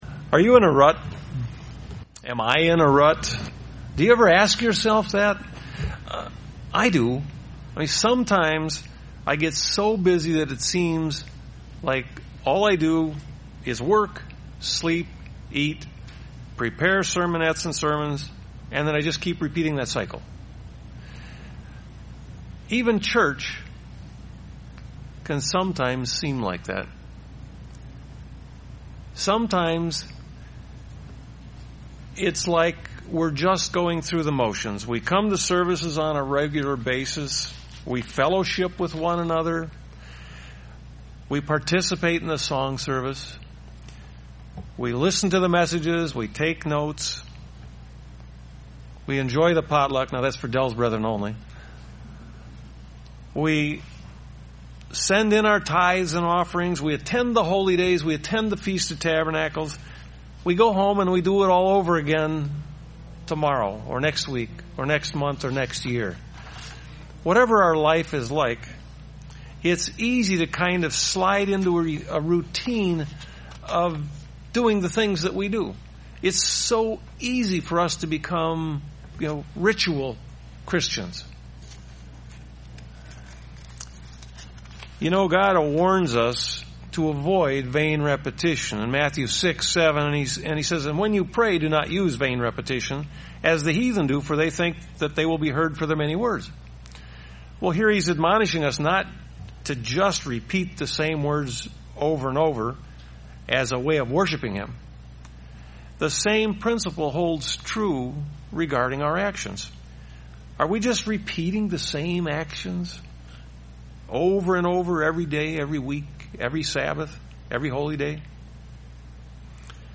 Print Anger and wrath UCG Sermon